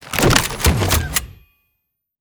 CosmicRageSounds / wav / general / combat / weapons / mgun / draw.wav